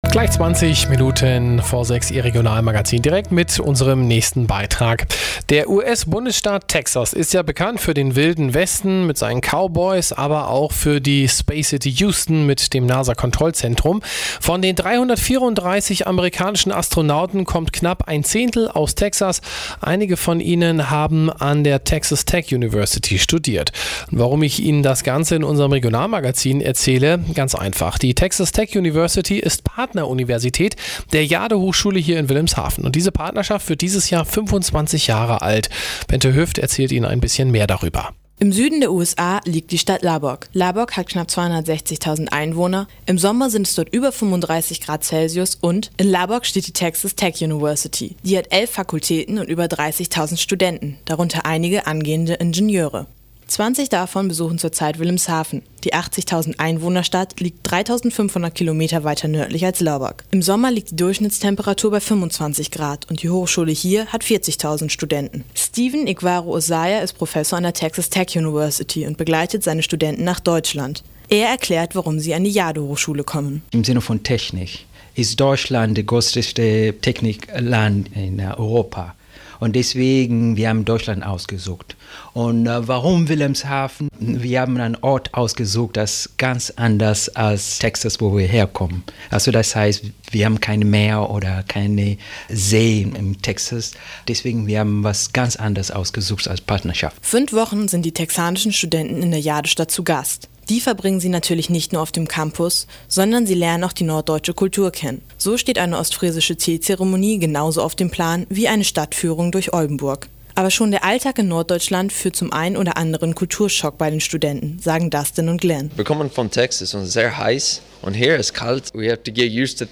Radiobeitrag von Radio Jade: Dateien: 240712_TexasTech.mp3 5,00 Mi